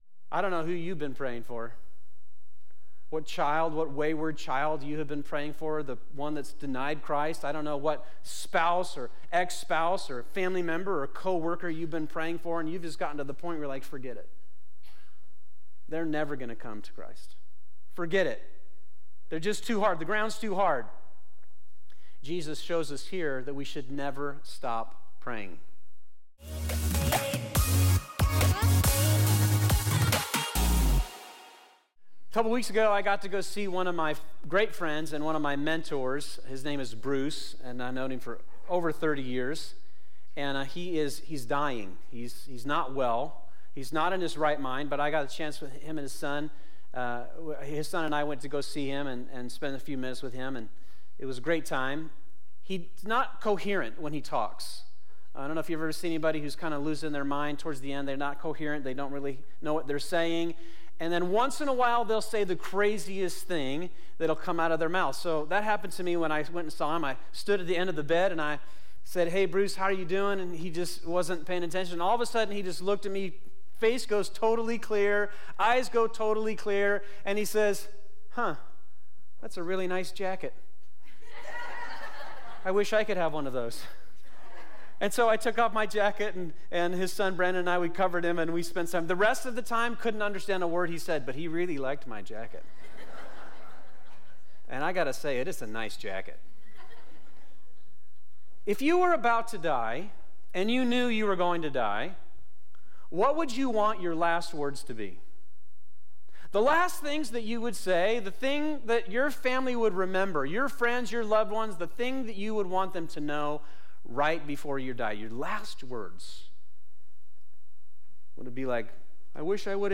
In this sermon we examine his prayer, "Father, forgive them," and ask: why did Jesus pray this?